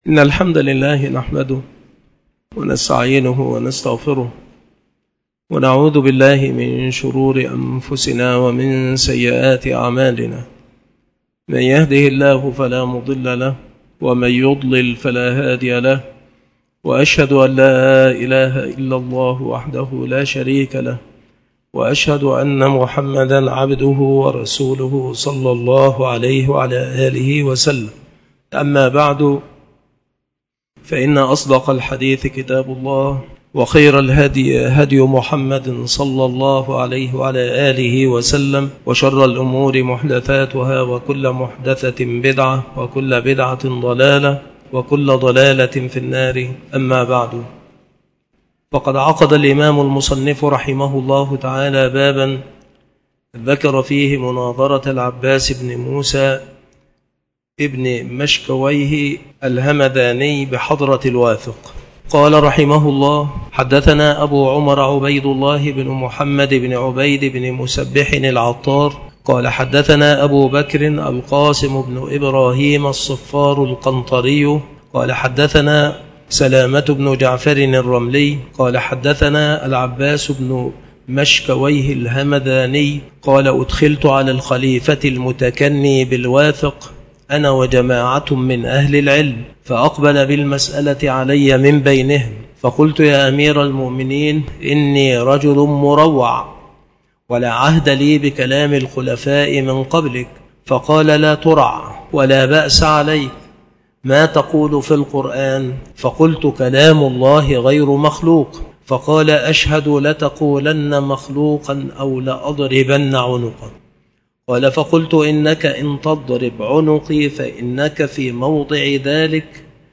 هذه المحاضرة
مكان إلقاء هذه المحاضرة بالمسجد الشرقي - سبك الأحد - أشمون - محافظة المنوفية - مصر